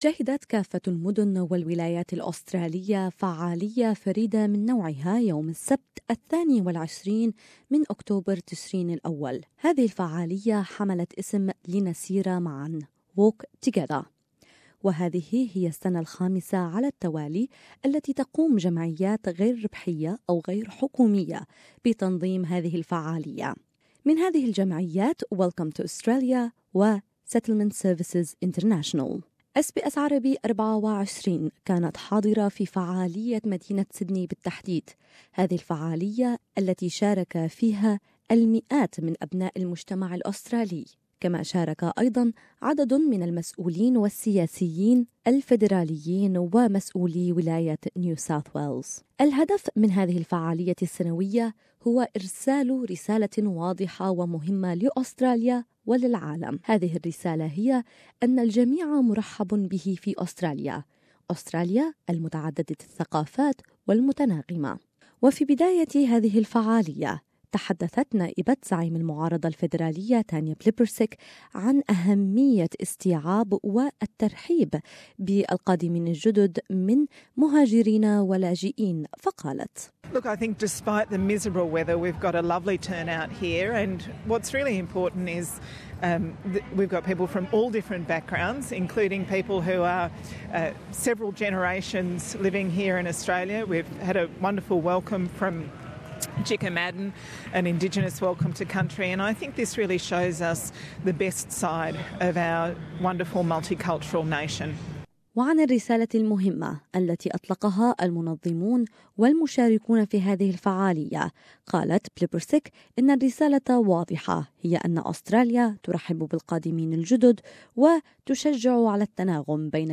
Walk Together 2016 was a huge celebration of diversity and a loud declaration from thousands of Australians that Australians can be a nation known for our compassion, generosity and welcome. We share our future was the main message. More in this report